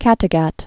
(kătĭ-găt)